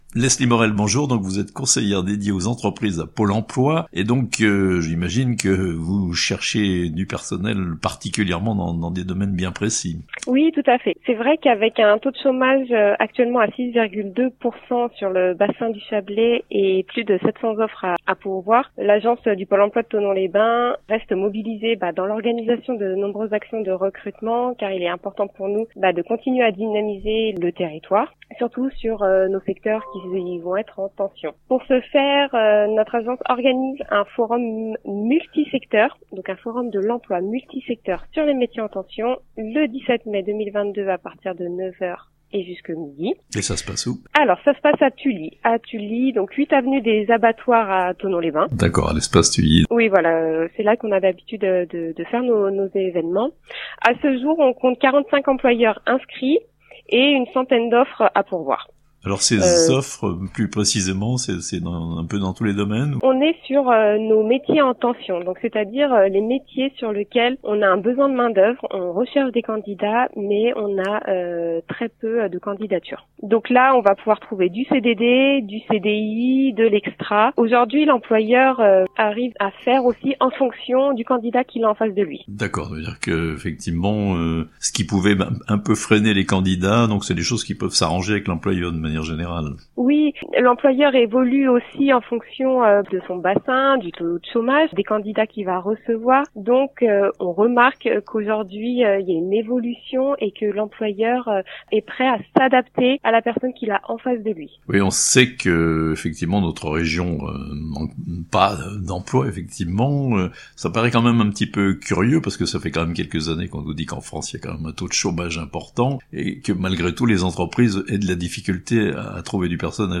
Un forum pour trouver un emploi en Chablais (interview)